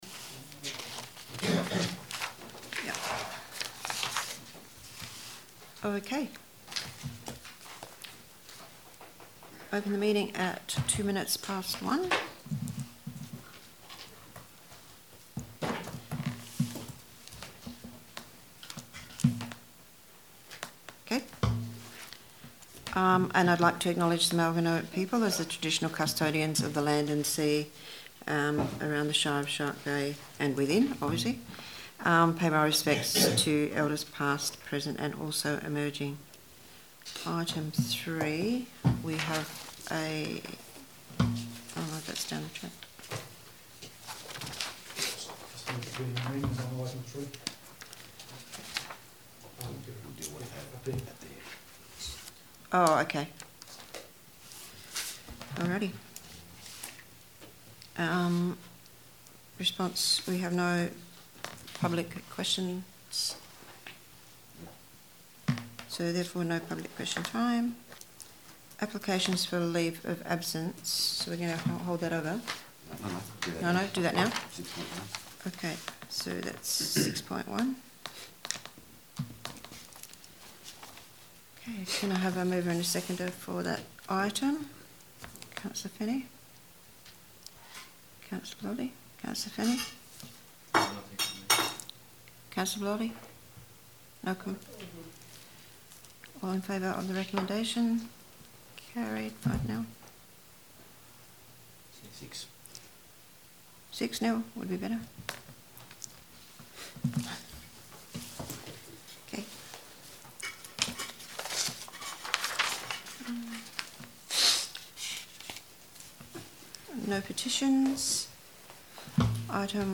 Ordinary Council Meetings
Shire of Shark Bay Council meetings are held at 3pm on the last Wednesday of every month except December when it is held on the third Tuesday.